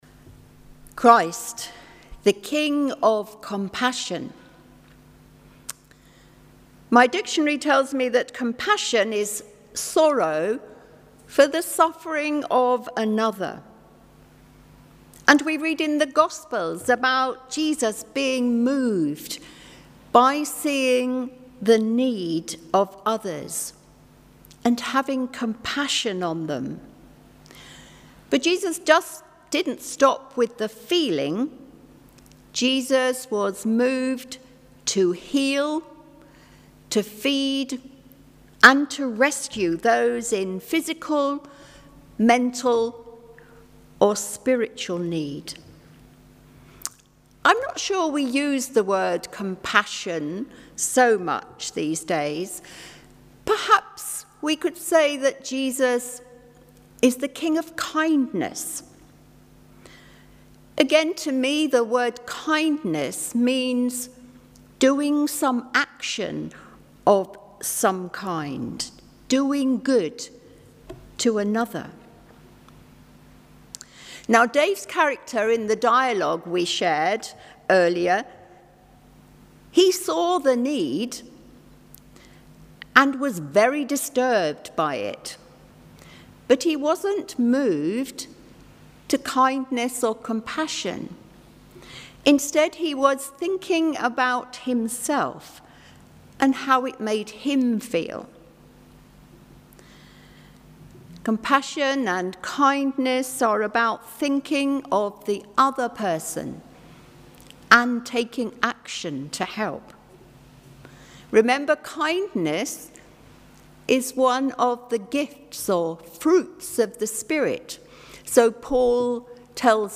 Online Worship
latestsermon-3.mp3